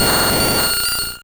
Cri de Rafflesia dans Pokémon Rouge et Bleu.